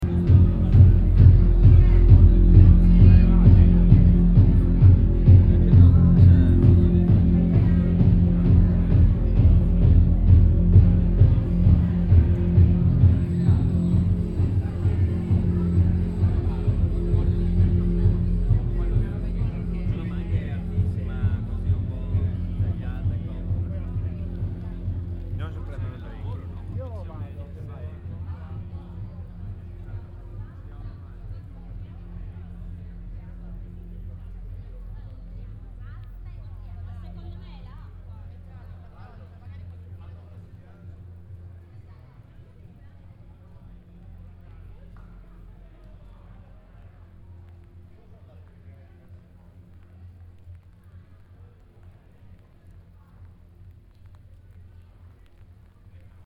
- Apprendere Per Produrre Verde E-mail Rumore Fuori piove Dentro si balla, fuori piove Torino, Via Sant'Agostino
Microfoni binaurali stereo SOUNDMAN OKM II-K / Registratore ZOOM H4n